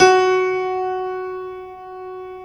Index of /90_sSampleCDs/InVision Interactive - Lightware VOL-1 - Instruments & Percussions/GRAND PIANO1